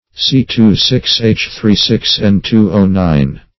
C26H36N2O9 - definition of C26H36N2O9 - synonyms, pronunciation, spelling from Free Dictionary
antimycin \an`ti*my"cin\ ([a^]n`t[i^]*m[imac]"s[i^]n), n.